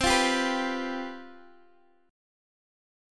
CM7sus4 chord